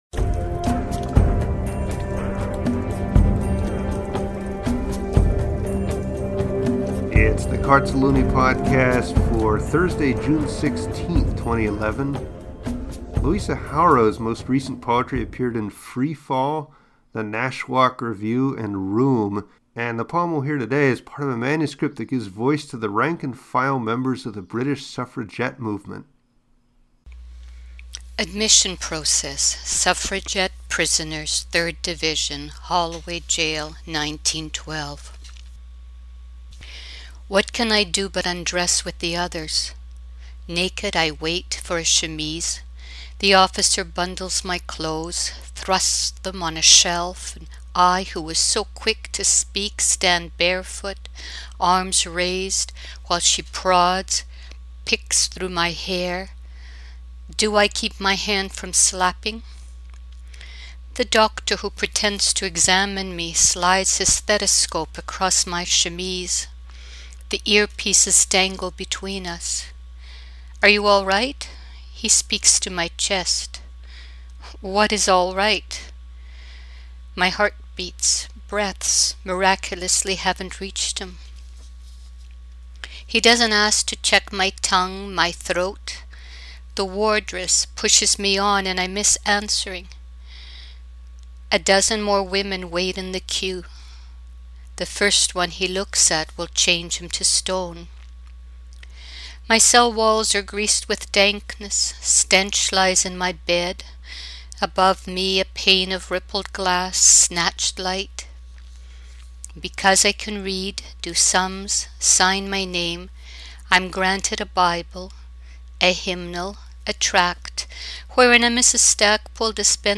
Read well, too.